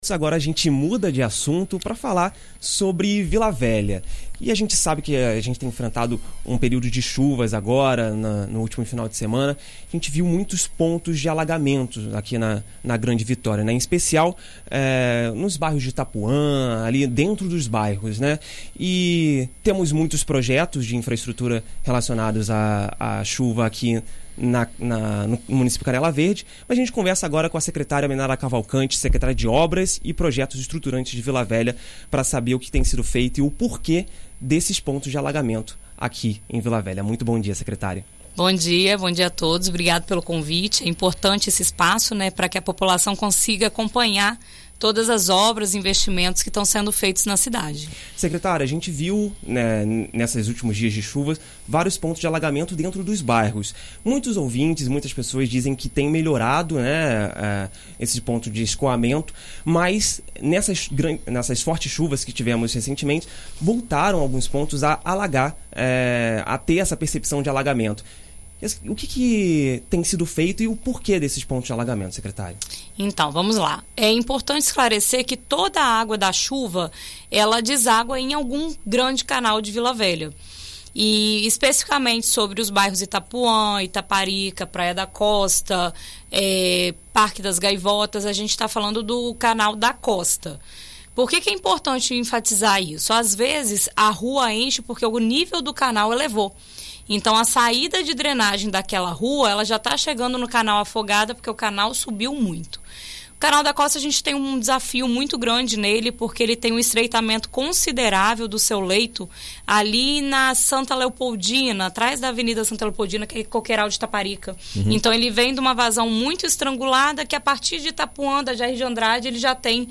Em entrevista à BandNews FM Espírito Santo nesta segunda-feira (19), a Secretária de Obras e Projetos Estruturantes de Vila Velha, Menara Cavalcante, fala sobre as medidas que estão sendo tomadas para evitar os alagamentos em Vila Velha, principalmente em alguns bairros específicos, como é o caso de Itapuã.